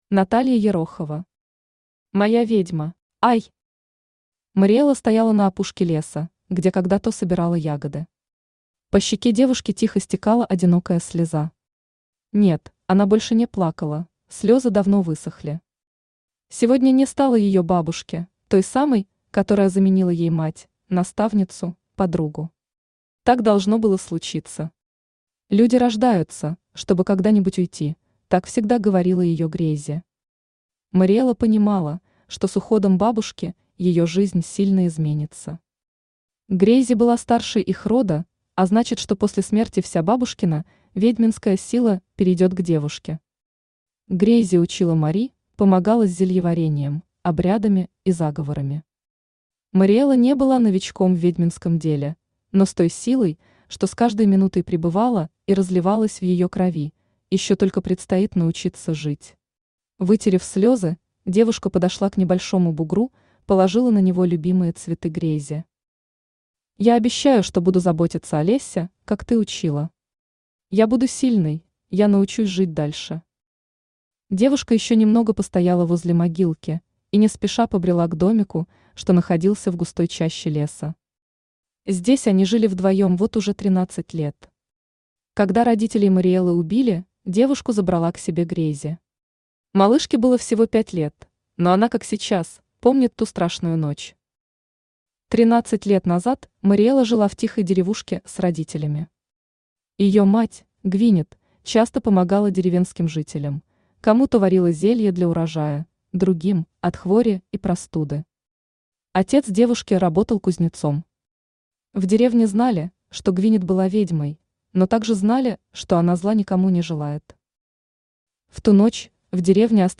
Аудиокнига Моя ведьма | Библиотека аудиокниг
Aудиокнига Моя ведьма Автор Наталья Александровна Ерохова Читает аудиокнигу Авточтец ЛитРес.